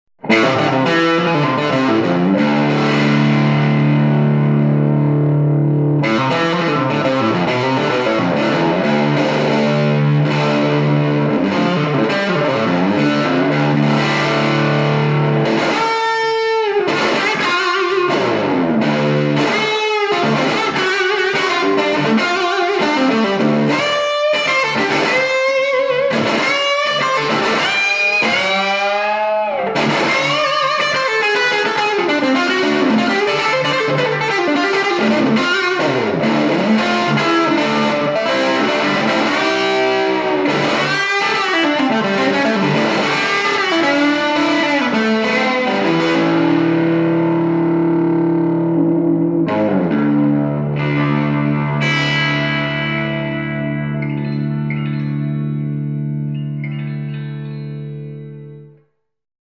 Room Les Paul High Gain 1   .59